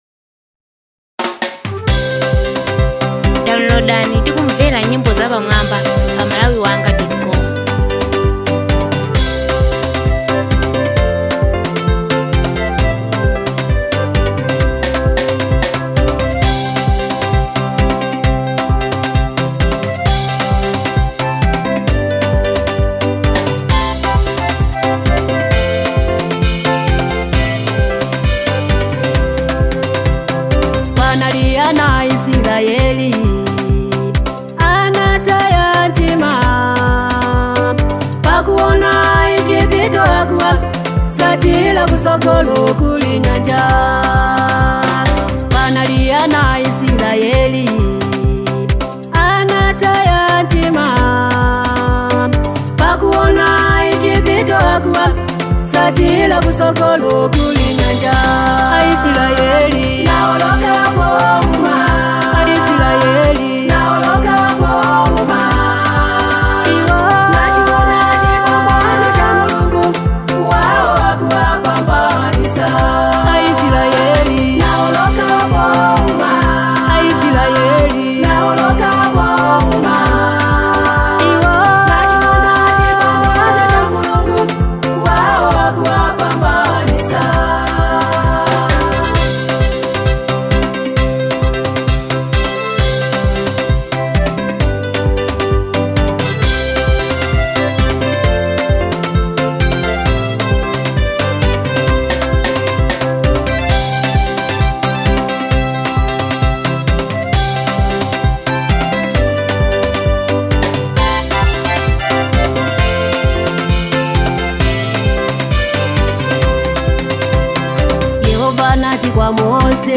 Malawian Gospel Artist